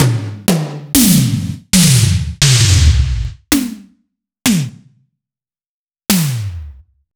013_Toms.wav